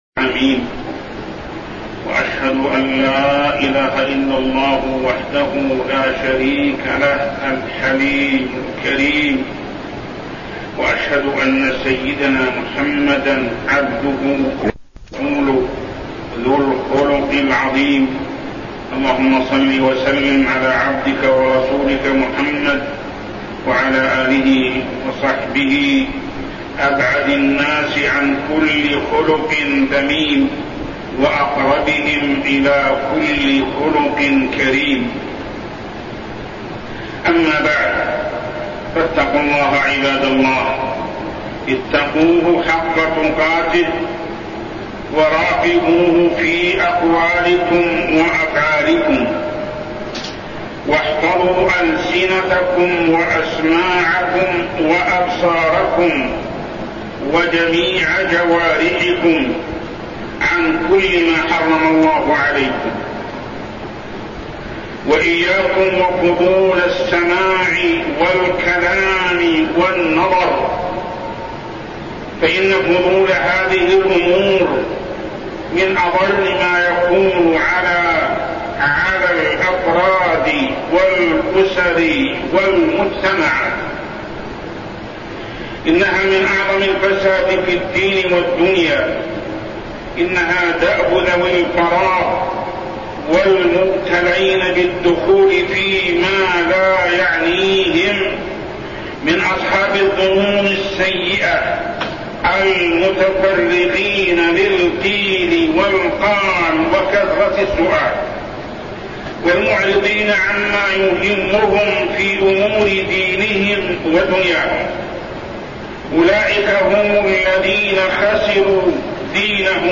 تاريخ النشر ١٥ محرم ١٤١٢ هـ المكان: المسجد الحرام الشيخ: محمد بن عبد الله السبيل محمد بن عبد الله السبيل حفظ الجوارح